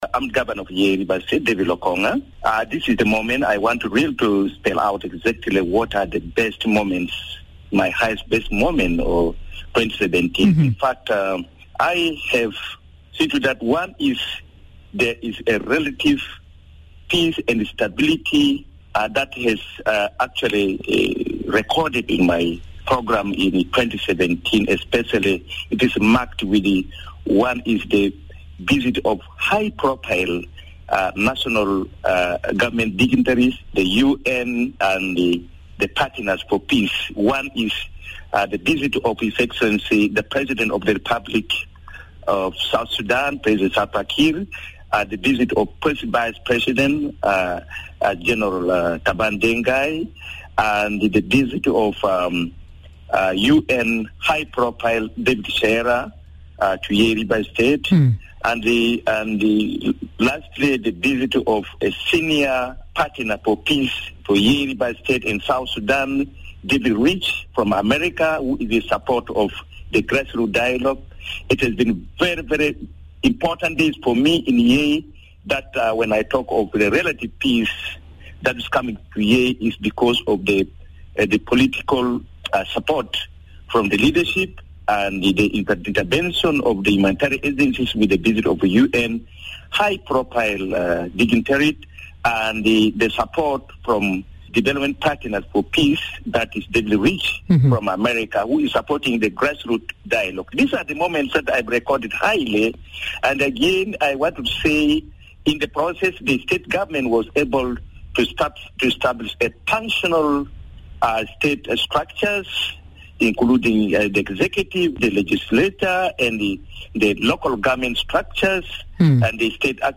Lokonga was speaking on Miraya Breakfast show about his hopes and dreams for the New Year. The Governor stressed the need for peace, describing it as the major gateway to prosperity in this country.